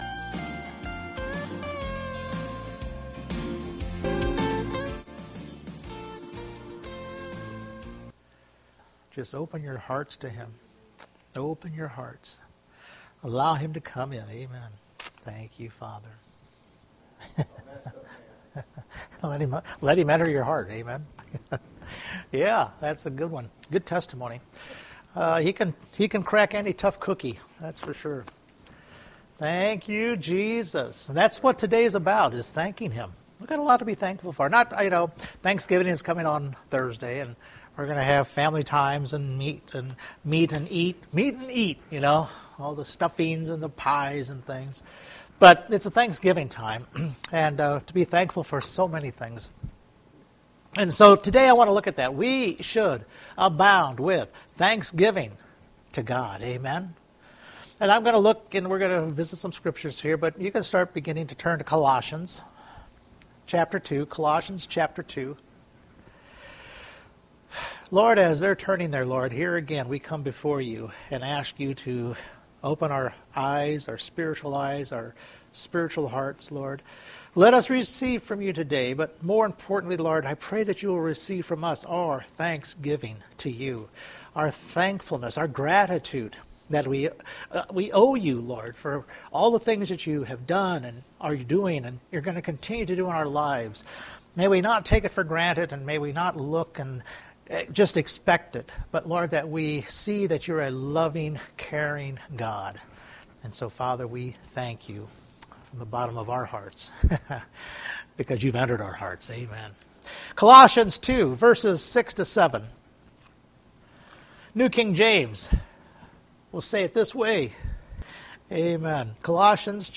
Colossians 2:6-7 Service Type: Sunday Morning Thanksgiving holiday reminds us to review our gratitude and thankfulness to God…